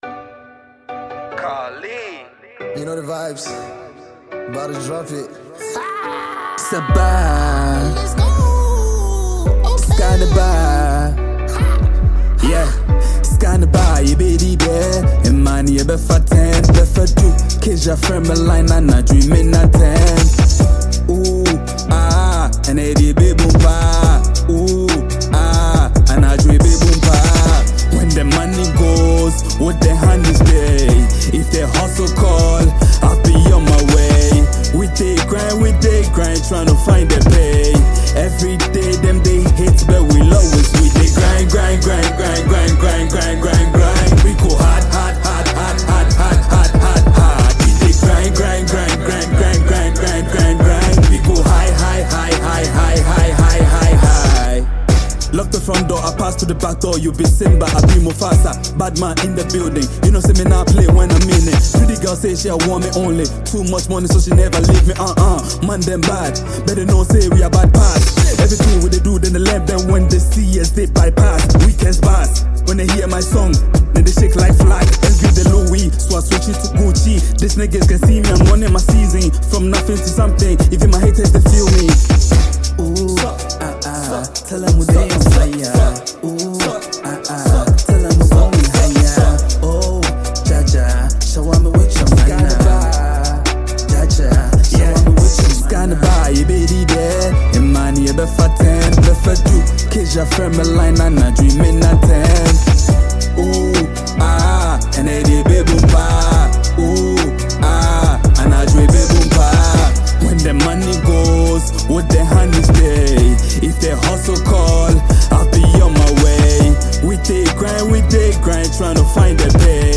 hip-hop
energetic yet melodic beat